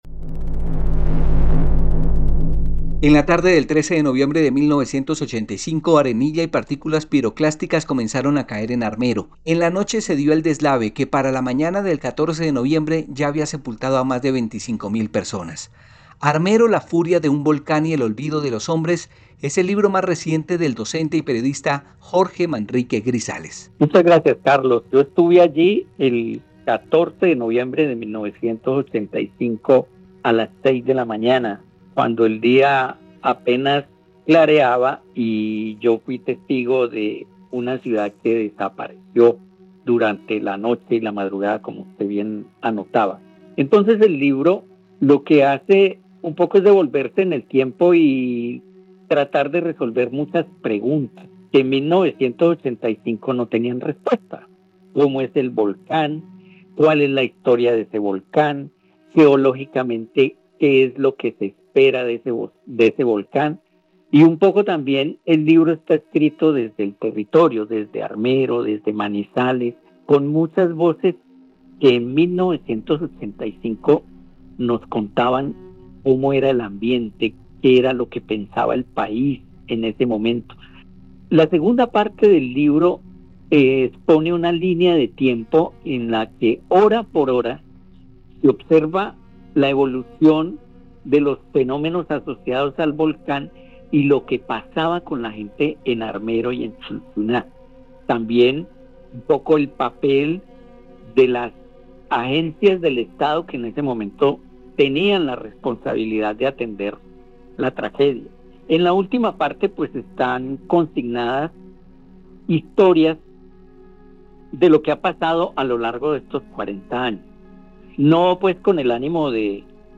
Conmemoración